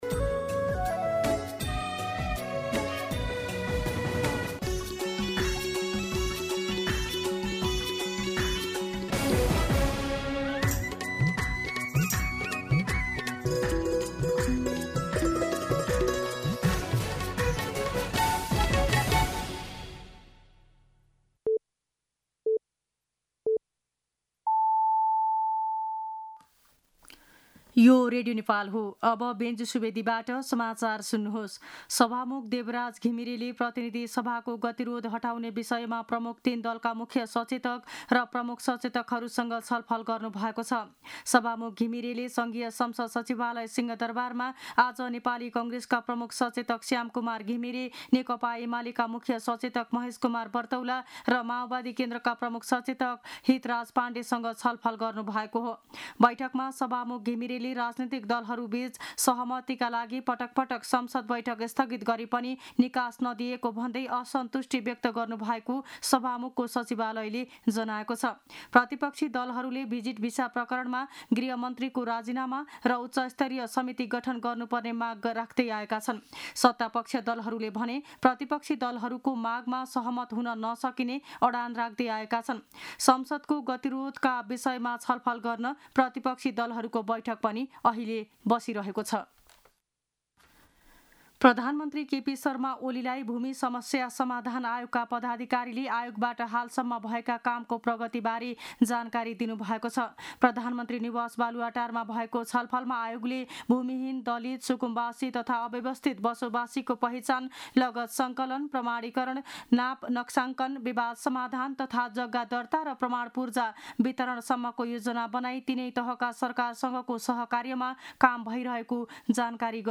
दिउँसो १ बजेको नेपाली समाचार : २५ जेठ , २०८२